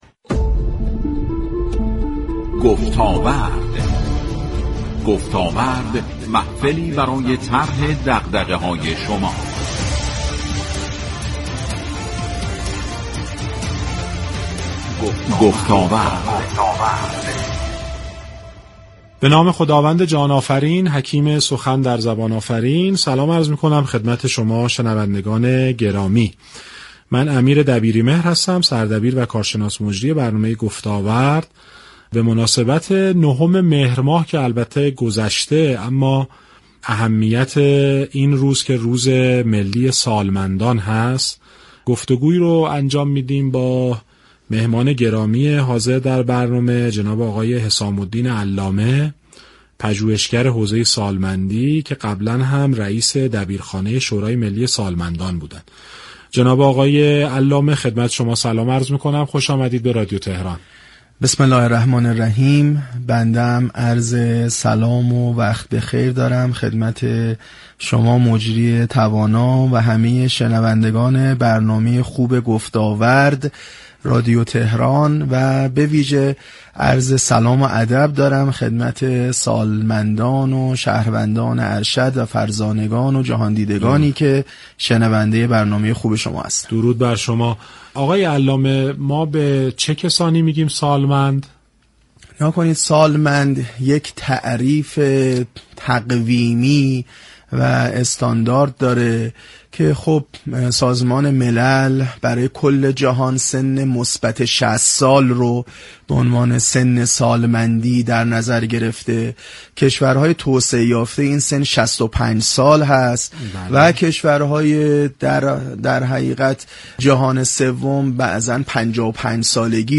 بر روی آنتن رادیو تهران رفت